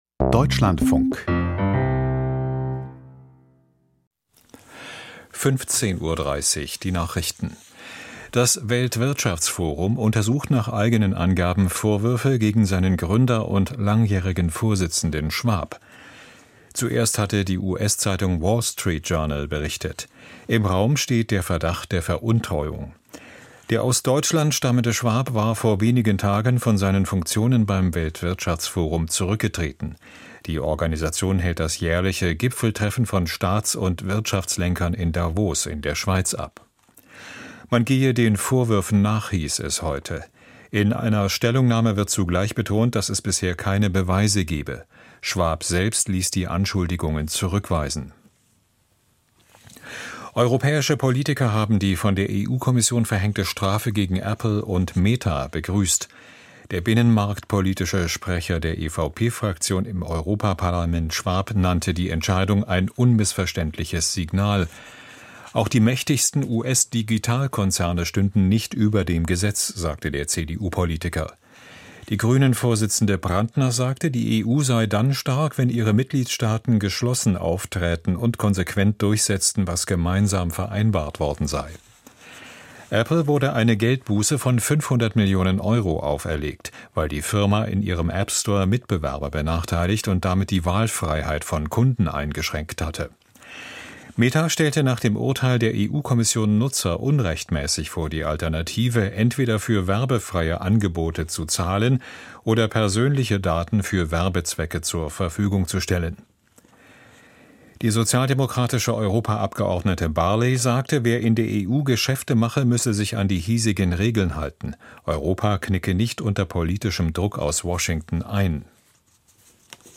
Die Deutschlandfunk-Nachrichten vom 23.04.2025, 15:30 Uhr